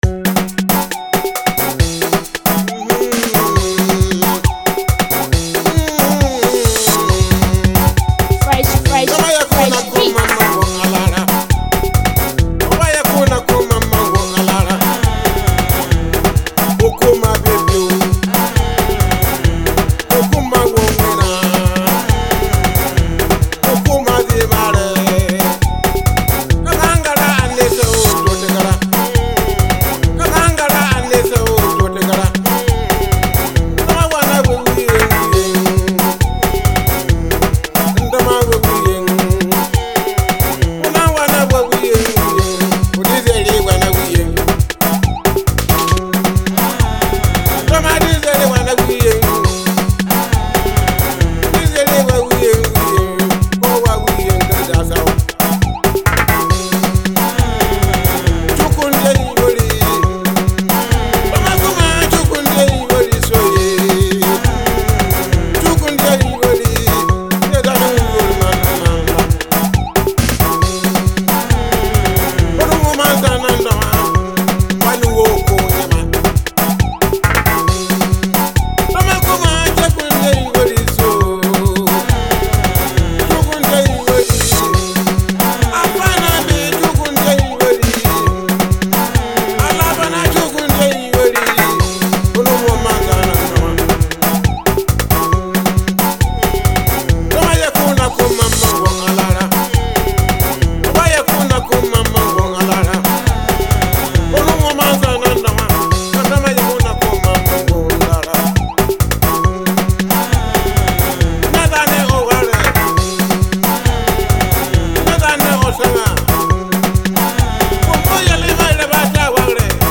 inspirational music